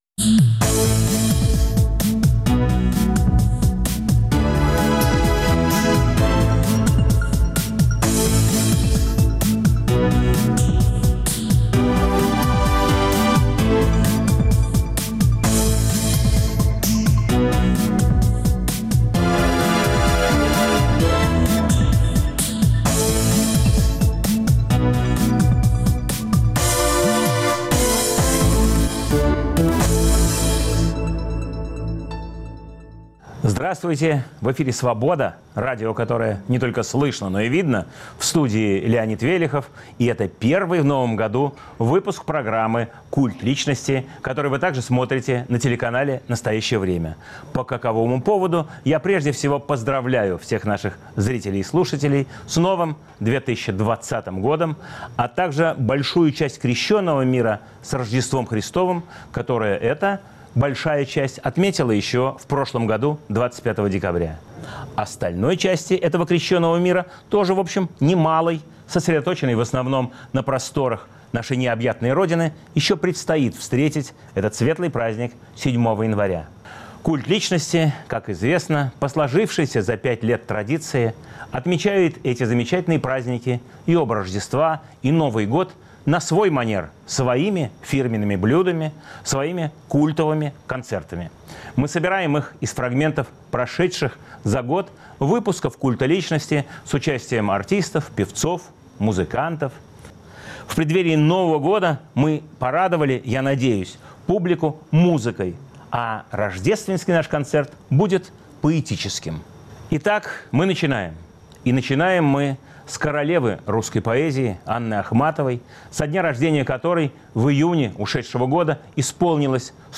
В Рождественском «культовом» концерте звучат стихи Анны Ахматовой, Бориса Слуцкого, Анатолия Наймана, песни на стихи Александра Вертинского и Владимира Высоцкого, проза Михаила Зощенко.